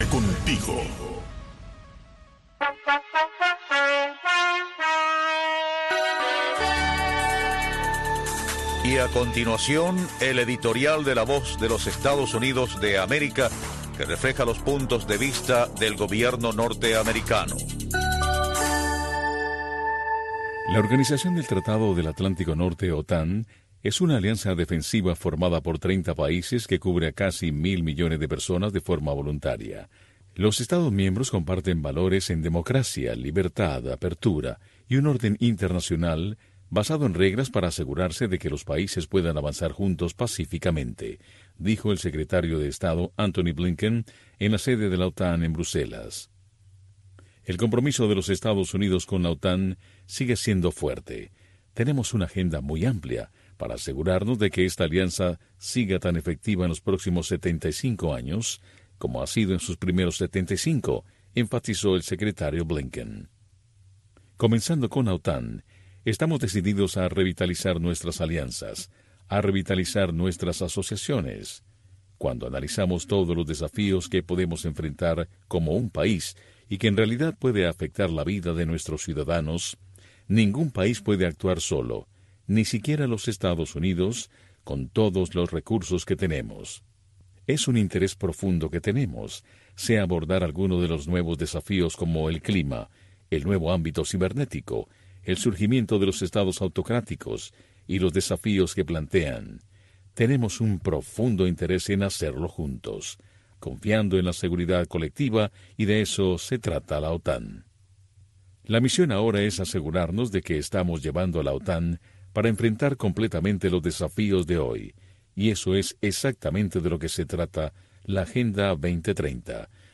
La Santa Misa
PROGRAMACIÓN EN-VIVO DESDE LA ERMITA DE LA CARIDAD